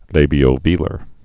(lābē-ō-vēlər)